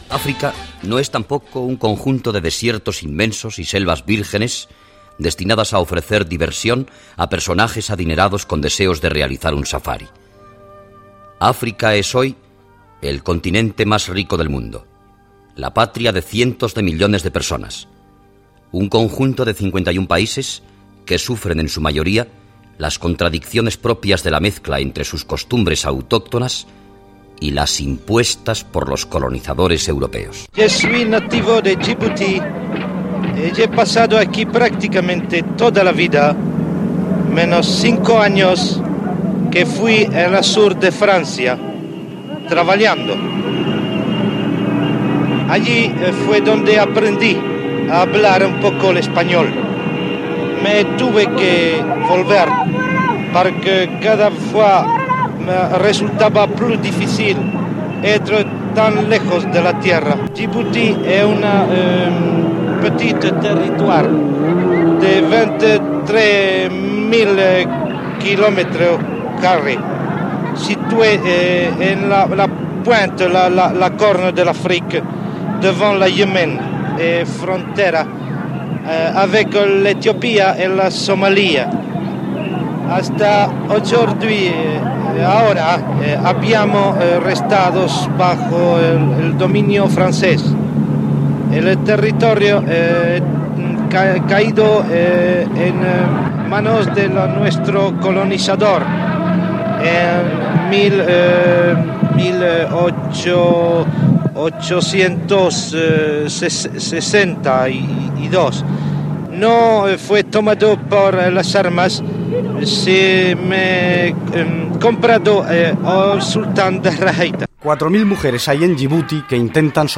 Reportatge sobre la República de Djibouti que s'ha independitzat de França
Informatiu